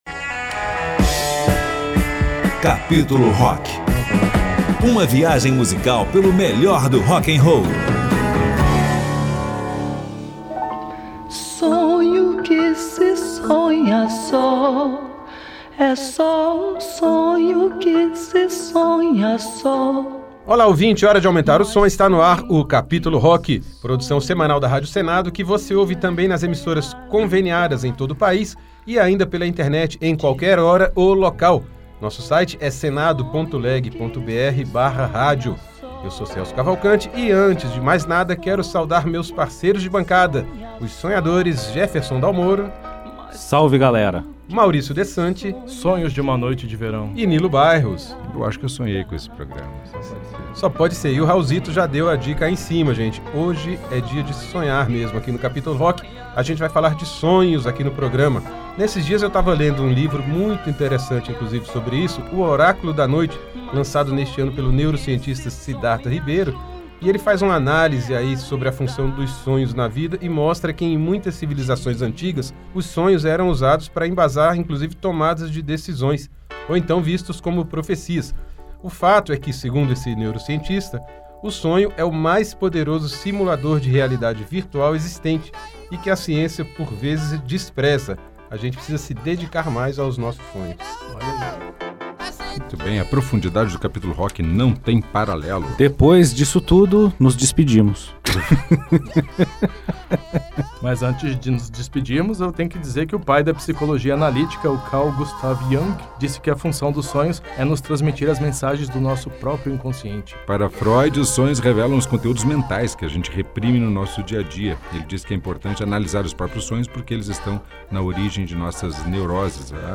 Rock'n'roll